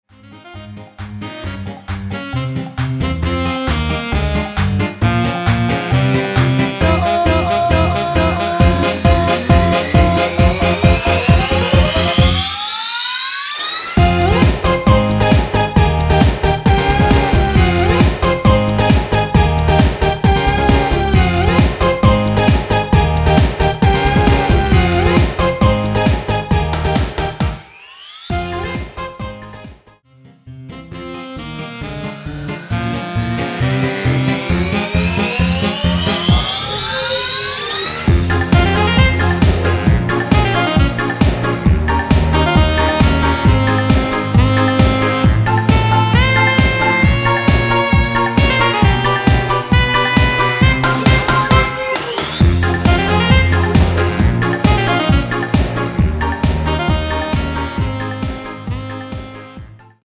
코러스가 거의 없어서 일반 코러스MR가격의 50% 가격으로 판매합니다.(미리듣기 참조)
Fm
◈ 곡명 옆 (-1)은 반음 내림, (+1)은 반음 올림 입니다.
앞부분30초, 뒷부분30초씩 편집해서 올려 드리고 있습니다.